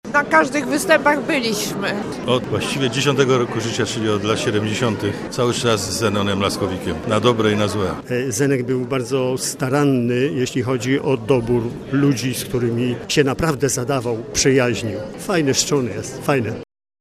W kuluarach Teatru sympatycy i przyjaciele artysty wspominali jego drogę scenicznej kariery, przypominając, jak wraz z Tey komentował realia PRL-u i bawił kolejne pokolenia.